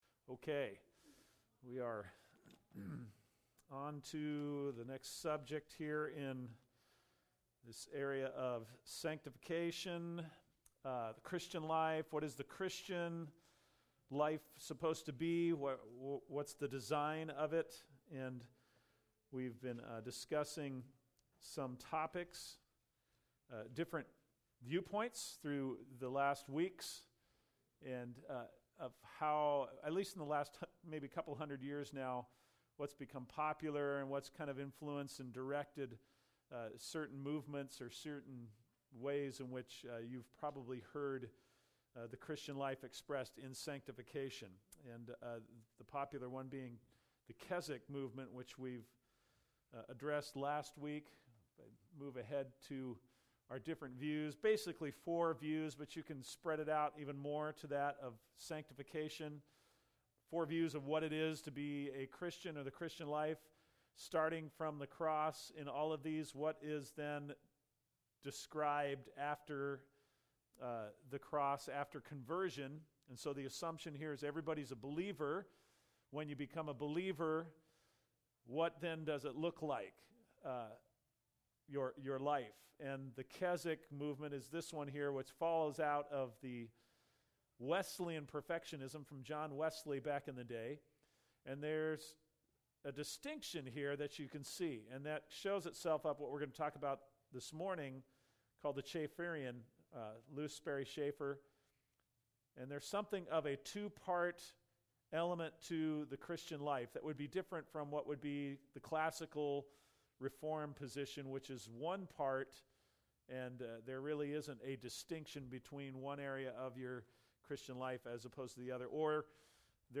Select Scriptures – Sanctification part 4 (sunday school)